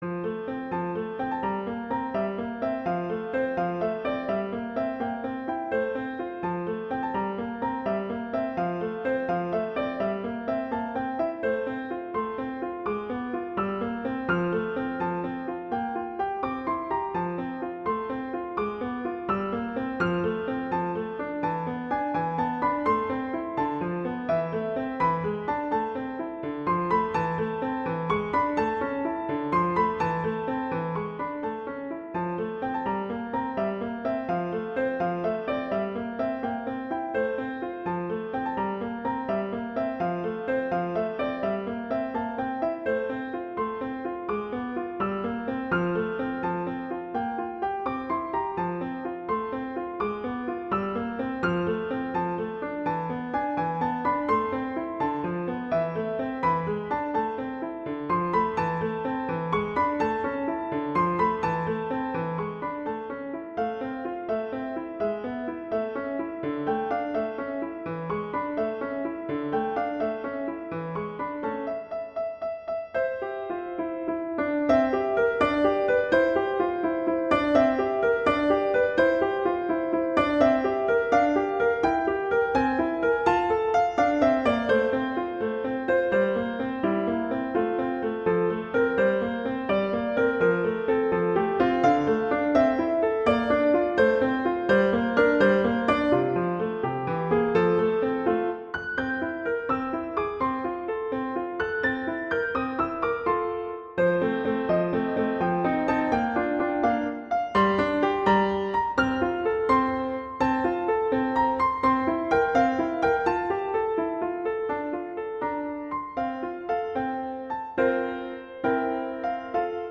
classical, french
F major
♩. = 84 BPM